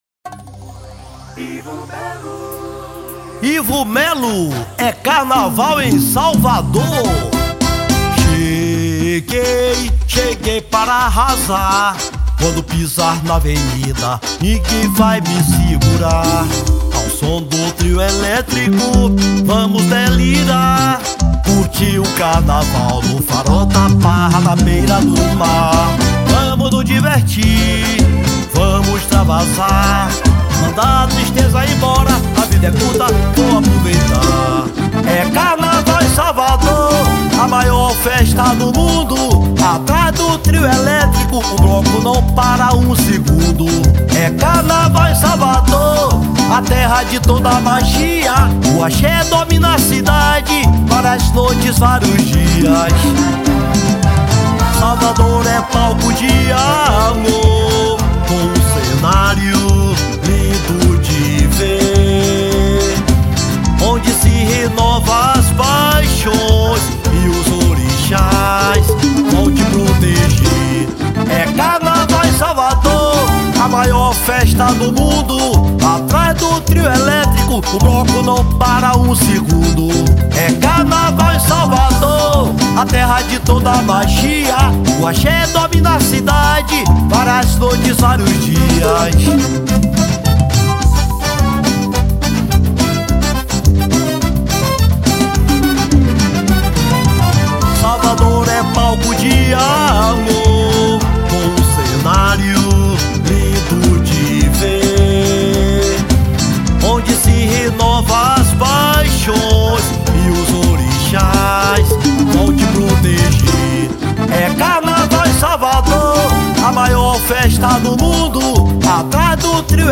EstiloSwingueira